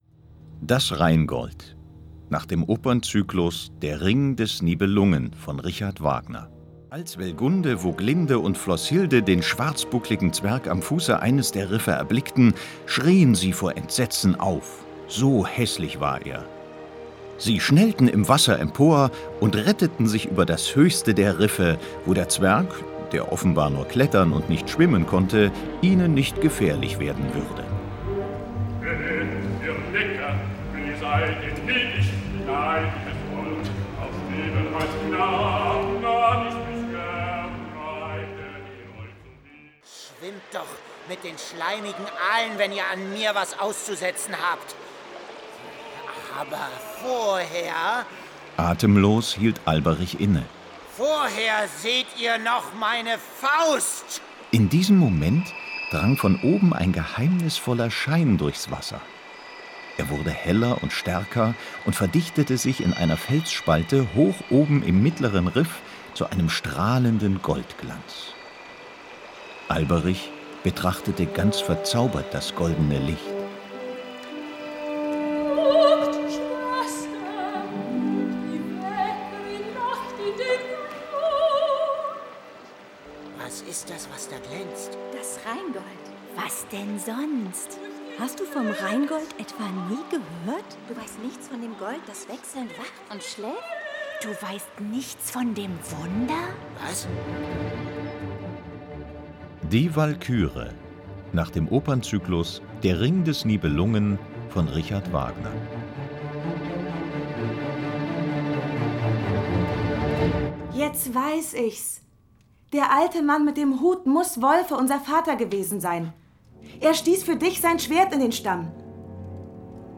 Der Ring des Nibelungen für kleine Hörer, sowie Parsifal und Der fliegende Holländer, Die ZEIT-Edition Hörspiel mit Opernmusik